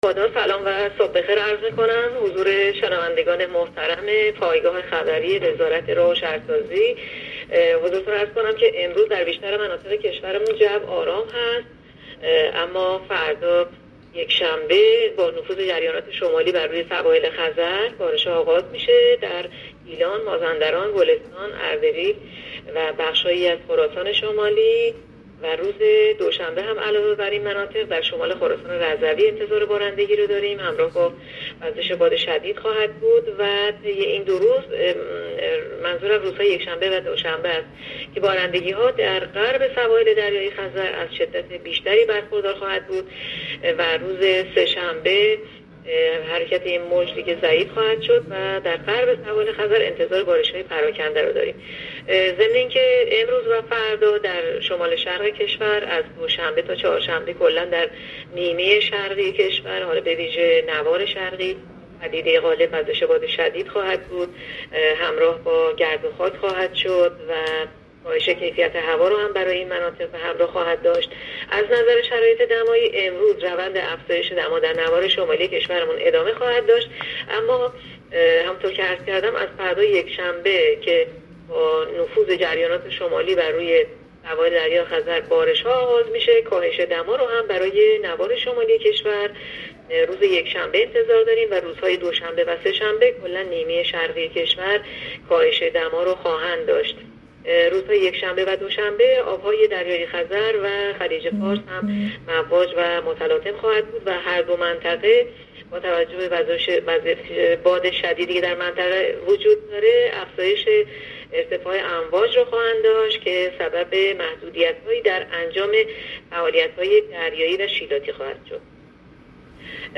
گزارش رادیو اینترنتی پایگاه‌ خبری از آخرین وضعیت آب‌وهوای دهم آبان؛